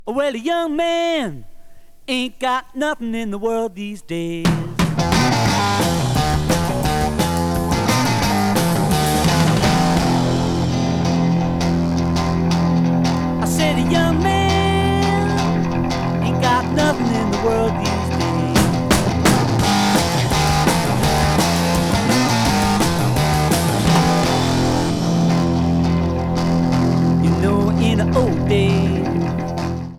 11) Third version, remastered.